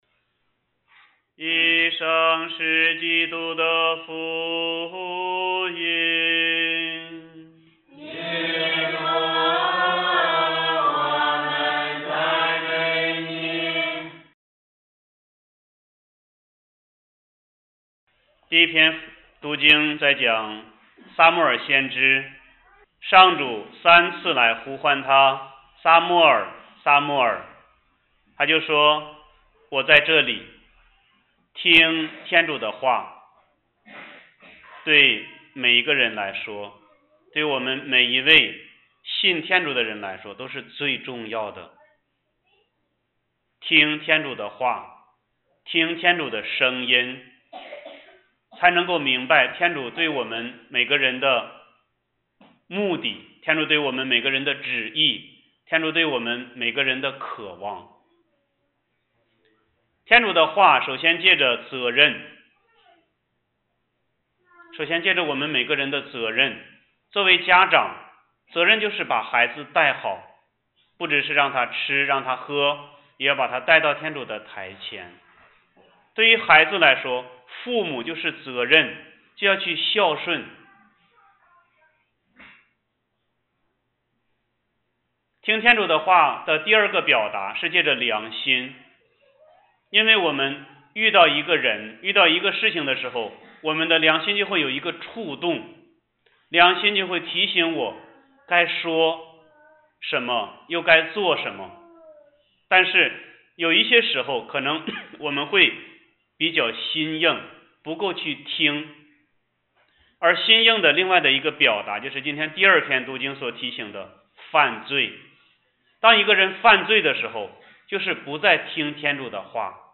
音频/讲道/避静道理/弥撒讲道/乙年 • 在线资料库